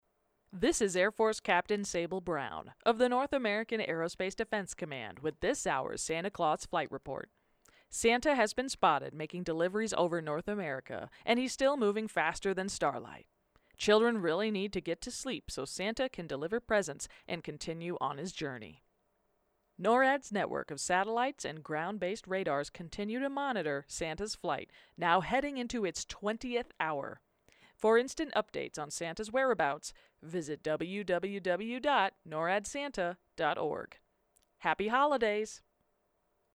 NTS 10pm MTS Radio update